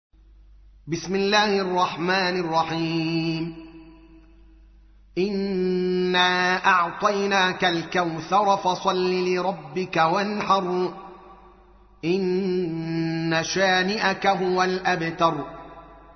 سورة الكوثر / القارئ الدوكالي محمد العالم / القرآن الكريم / موقع يا حسين